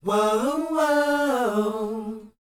WHOA A A U.wav